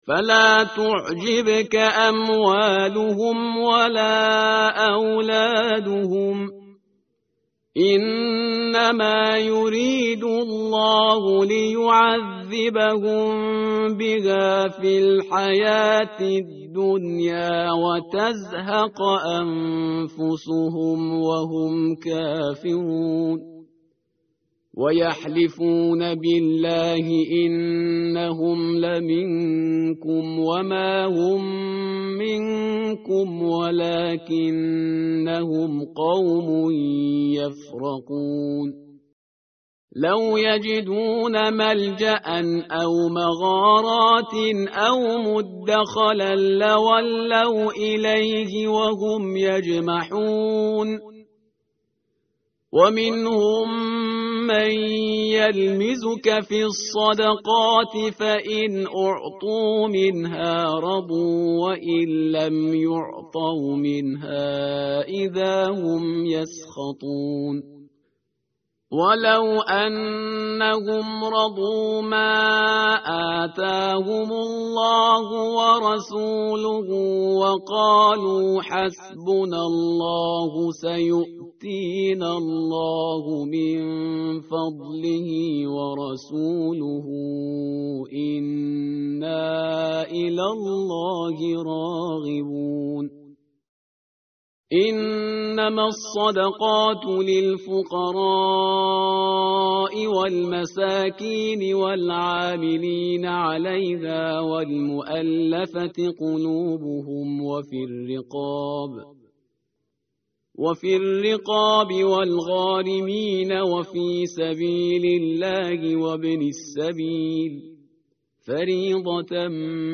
متن قرآن همراه باتلاوت قرآن و ترجمه
tartil_parhizgar_page_196.mp3